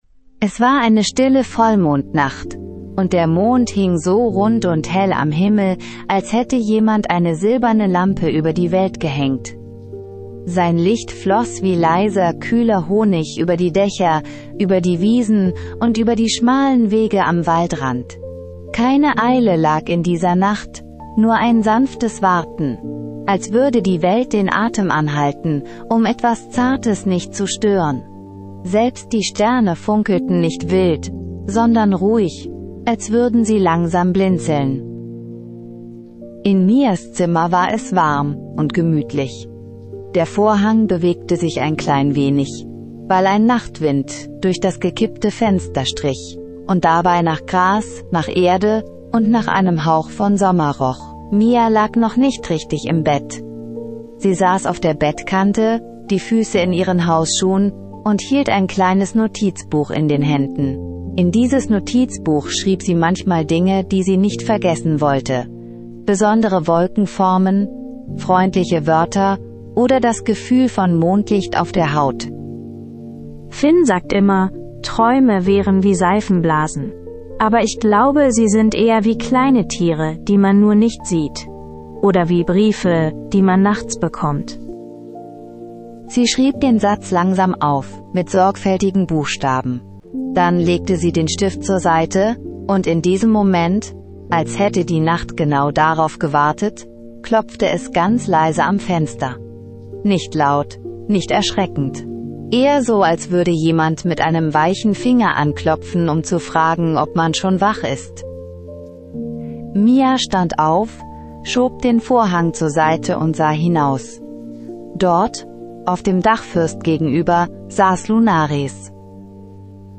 Magisches Einschlaf-Hörspiel für Kinder